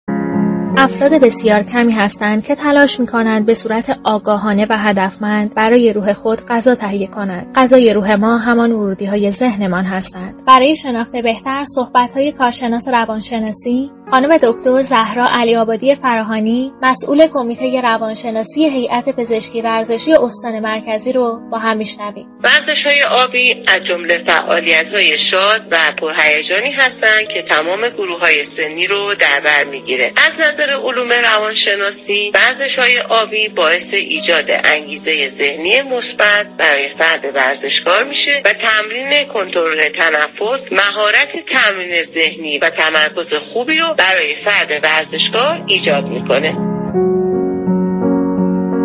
/گفت و گوی رادیویی/